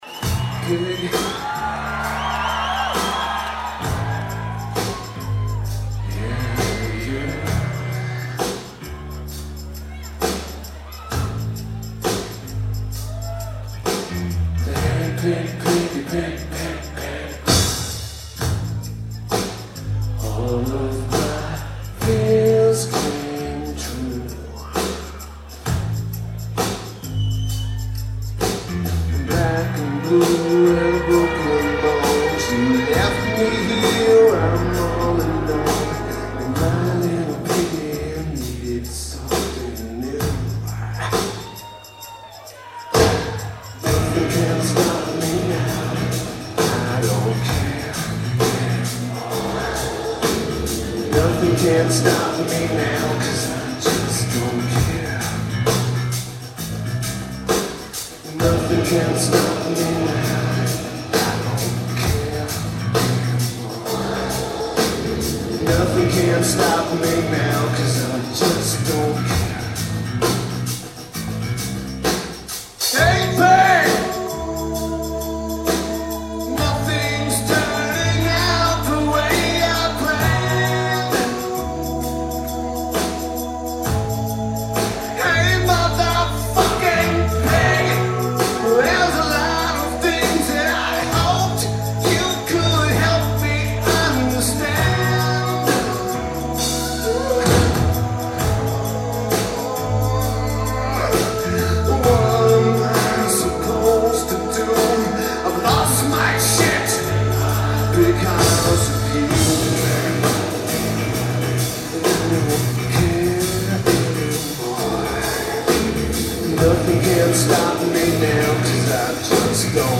The Astoria
Lineage: Audio - AUD (Sony ECM-717 + Sony MZ-N710 [LP2])
A fantastic recording.